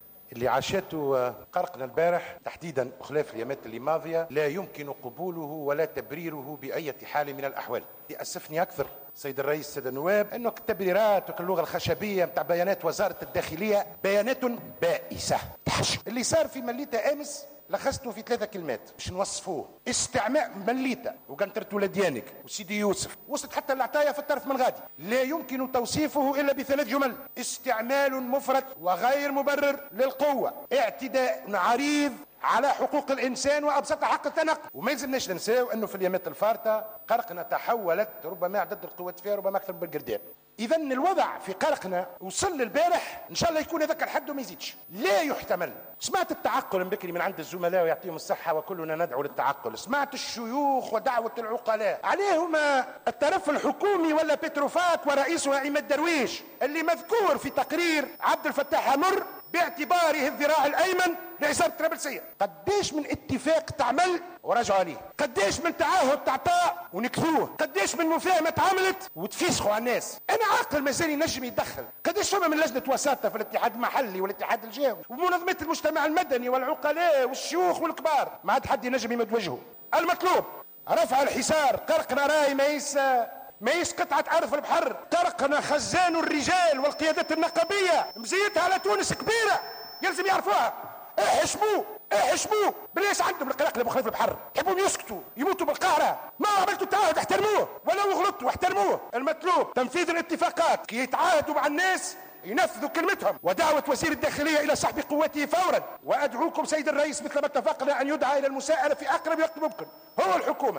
قال النائب عن الجبهة الشعبية أحمد الصديق خلال جلسة عامة عقدت اليوم الجمعة 15 أفريل 2016 بمجلس نواب الشعب إنه لا يمكن تبرير ما حدث أمس وفي الأيام الفارطة من استعمال "مفرط وغير مبرر للقوة" ضد المحتجين في قرقنة.